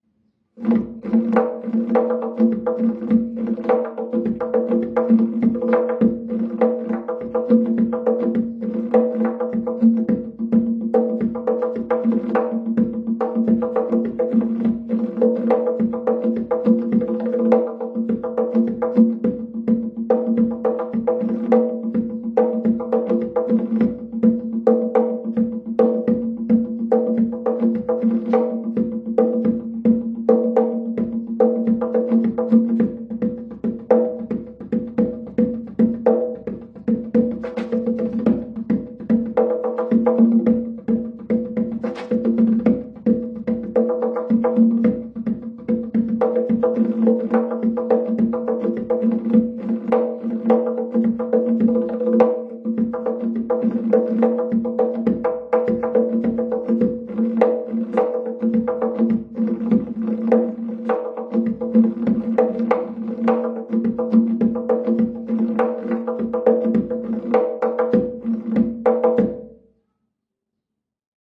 تنبک
تنبک یا تمبک یکی از سازهای کوبه‌ای ایرانی است.
این ساز پوستی، از نظر سازشناسی جزء طبل‌های جام ‌شکل محسوب می‌شود که با انگشتان دودست و با تکنیک هایی همچون تُم، بک، پلنگ و ریز نواخته می شود.
tonbak.mp3